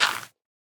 Minecraft Version Minecraft Version latest Latest Release | Latest Snapshot latest / assets / minecraft / sounds / block / composter / fill_success4.ogg Compare With Compare With Latest Release | Latest Snapshot
fill_success4.ogg